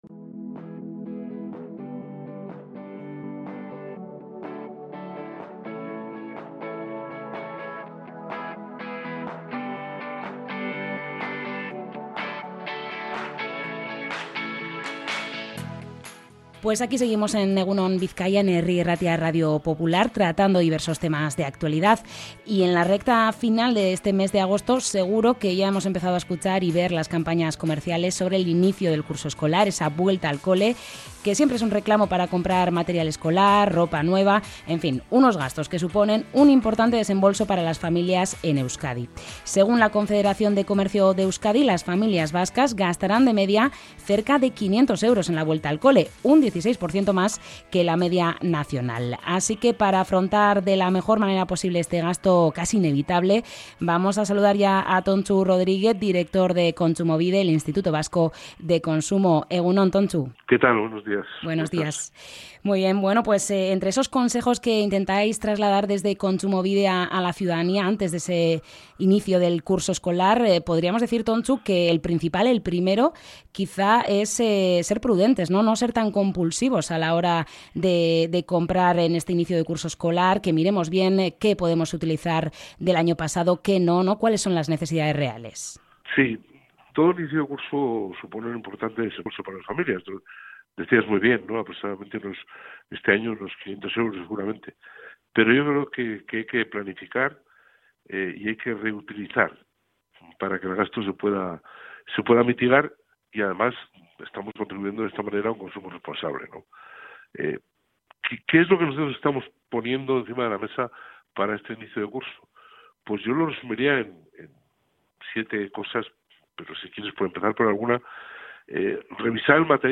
ENTREV-KONTSUMOBIDE-CON-SINTONIA-ENTRADA-Y-SALIDA.mp3